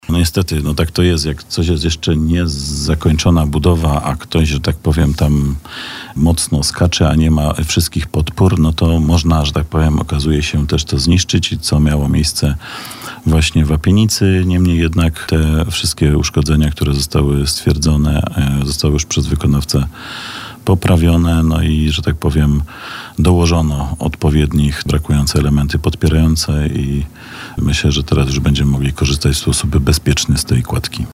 O sprawie mówił dzisiaj na naszej antenie zastępca prezydenta miasta Przemysław Kamiński.